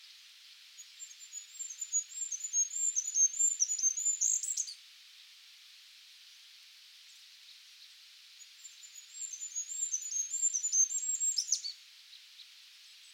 birds - An Anki deck to learn birds by their sounds